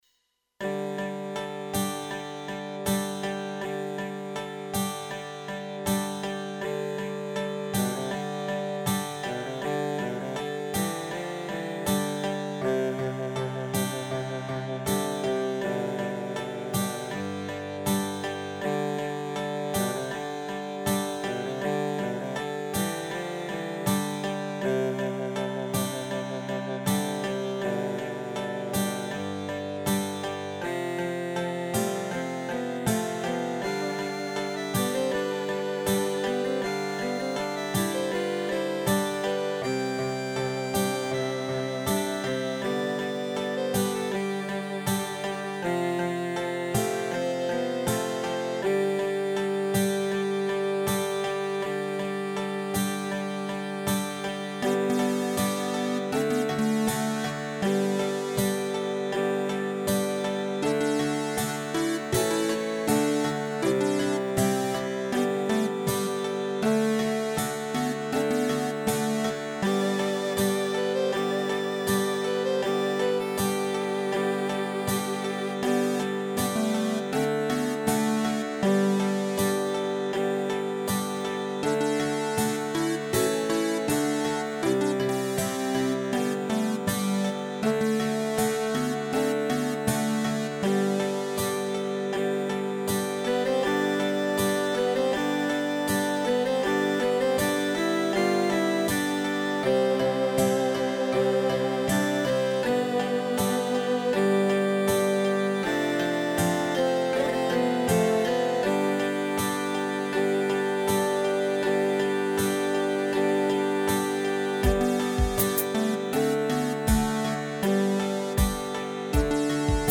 Rubrika: Pop, rock, beat